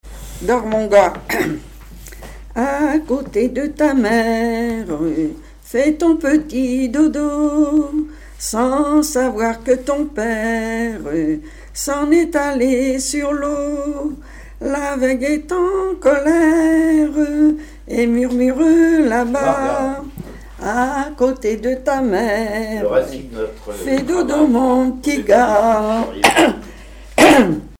Berceuses diverses
Chansons et commentaires
Pièce musicale inédite